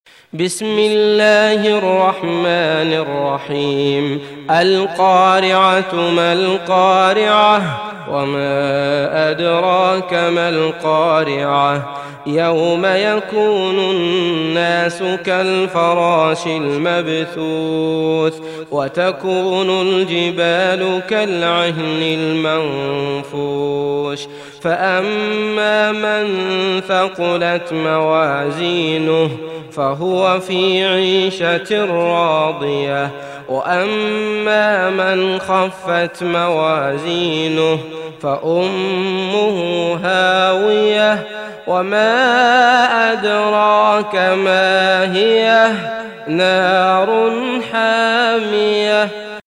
تحميل سورة القارعة mp3 بصوت عبد الله المطرود برواية حفص عن عاصم, تحميل استماع القرآن الكريم على الجوال mp3 كاملا بروابط مباشرة وسريعة